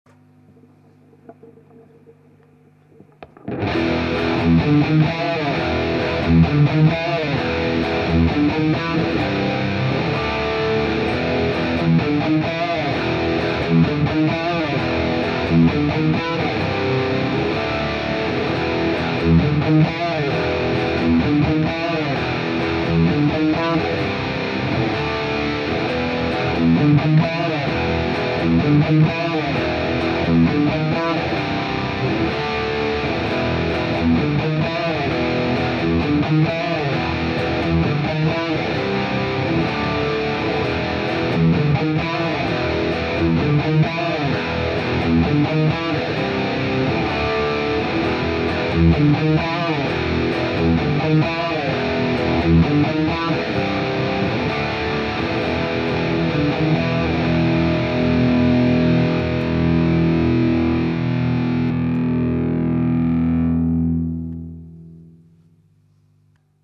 J'ai réalisé un sample vite fait sans aucune retouche(brut de chez brut), de mon matos N.O.S, un micro E906 devant un Greenback, 2 prises droite gauche/droite.